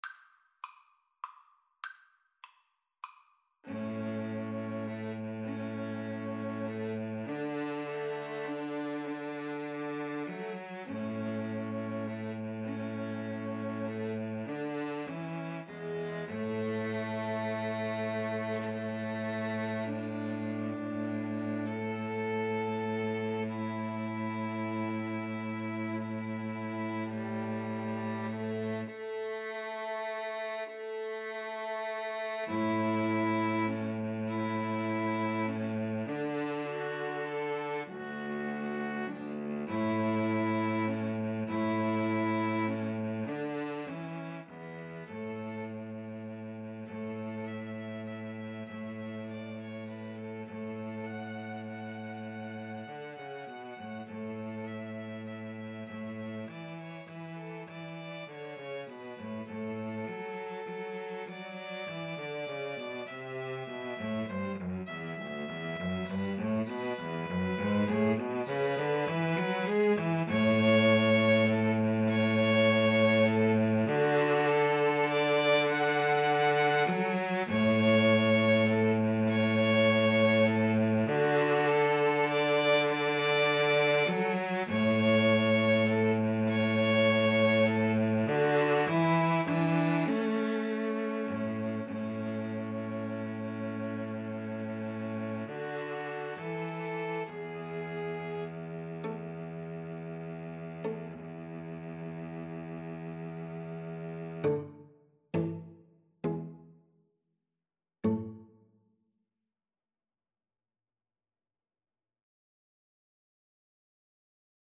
A major (Sounding Pitch) (View more A major Music for 2-Violins-Cello )
Gently = c. 100
Traditional (View more Traditional 2-Violins-Cello Music)